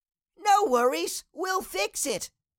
Cartoon Little Child, Voice, We Will Fix It Sound Effect Download | Gfx Sounds
Cartoon-little-child-voice-we-will-fix-it.mp3